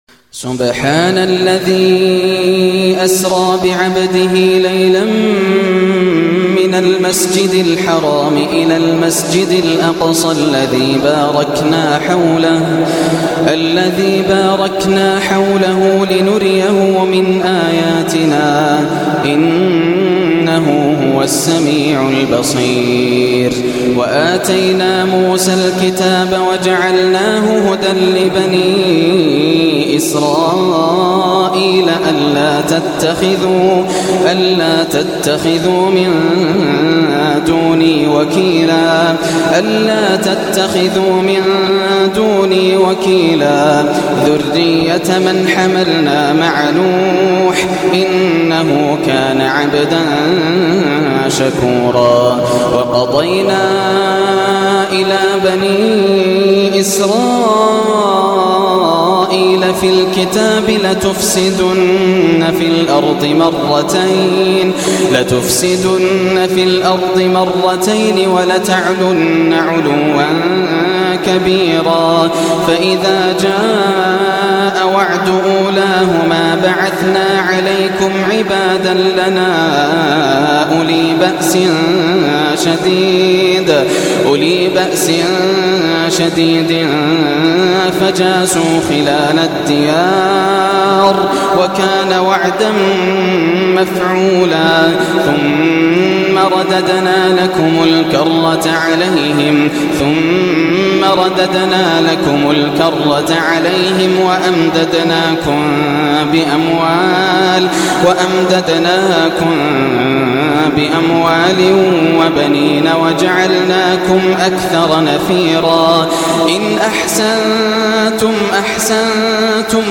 سورة الإسراء > السور المكتملة > رمضان 1425 هـ > التراويح - تلاوات ياسر الدوسري